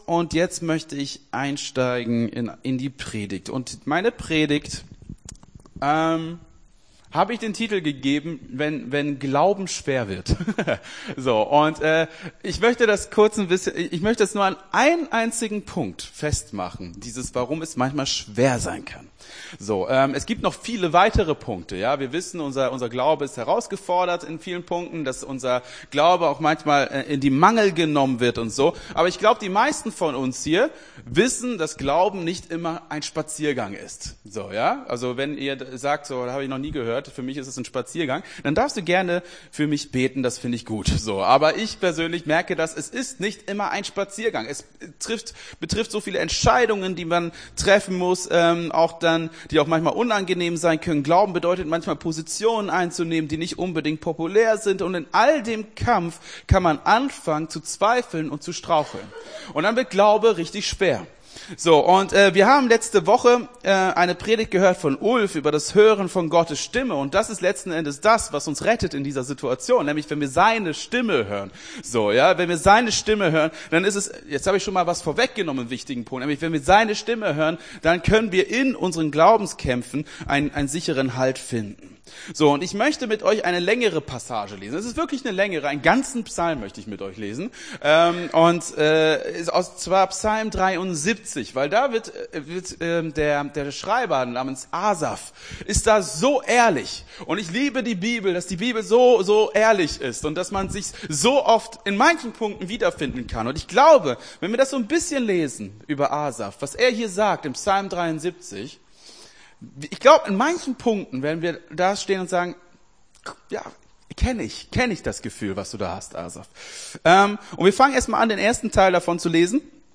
Gottesdienst 05.03.23 - FCG Hagen